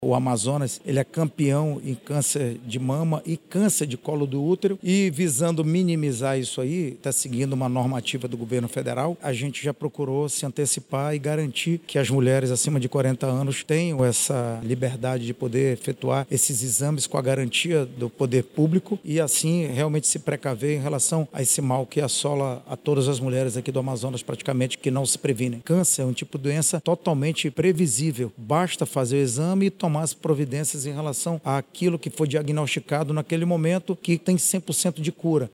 O autor da primeira propositura, o vereador Coronel Rosses, do PL, destaca que a medida é um instrumento importante de prevenção ao câncer de mama.